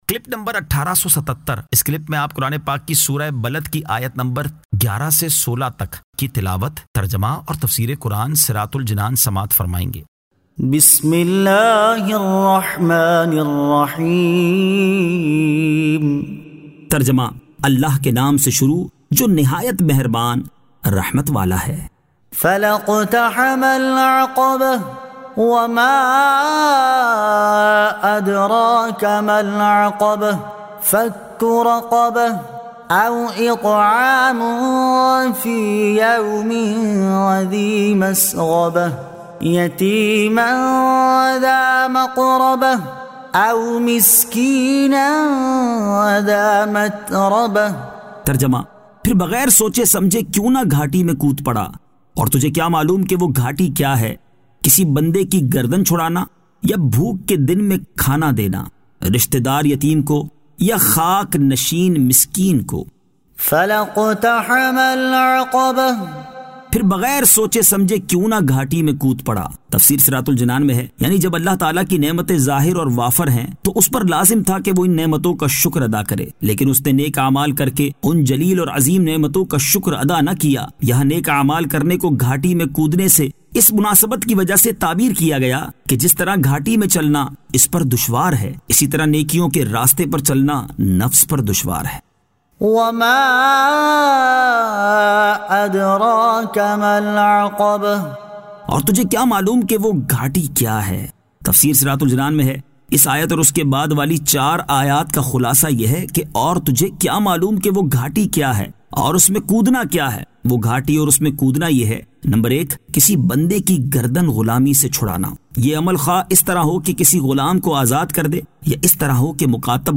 Surah Al-Balad 11 To 16 Tilawat , Tarjama , Tafseer